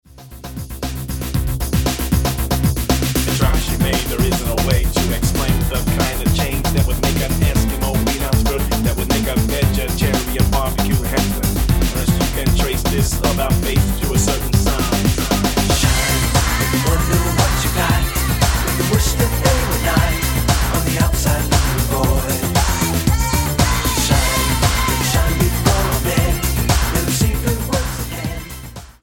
Style: Dance/Electronic Approach: Praise & Worship